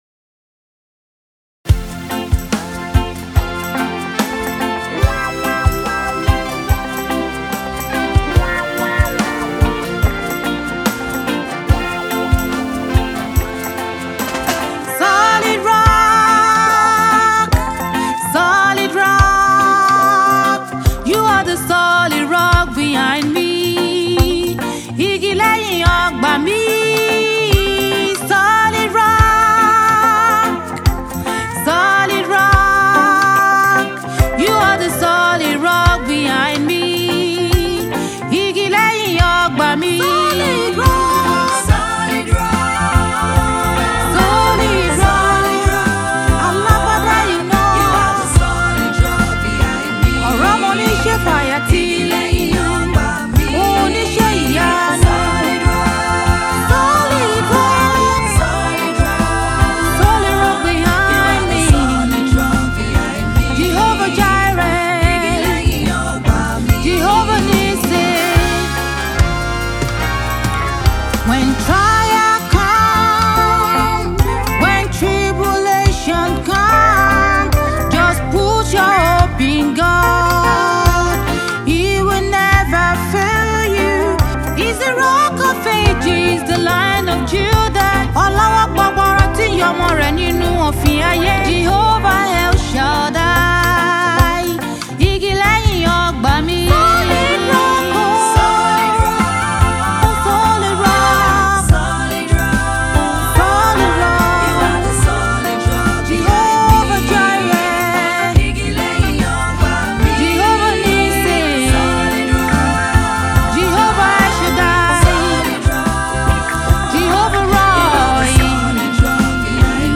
gospel artist